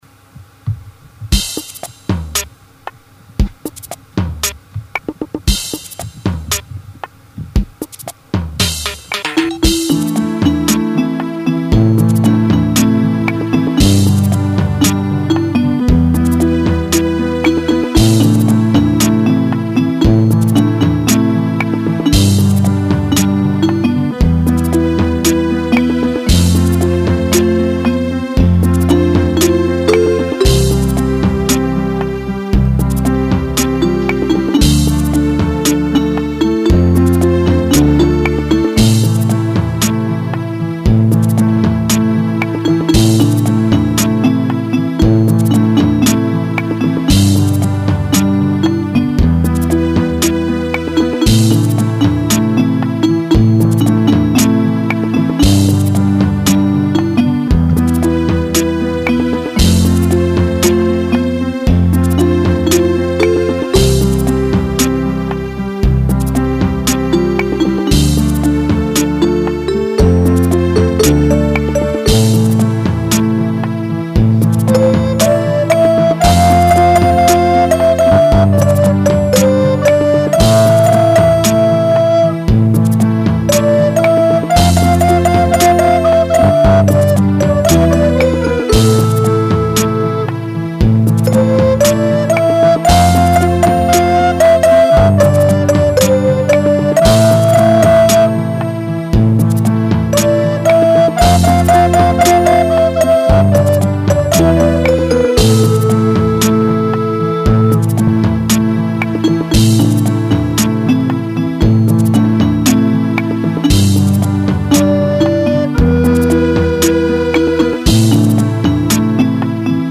אני בחלילית אלט
באורגנית.
ממש יפה שקט מרגיע ומרגש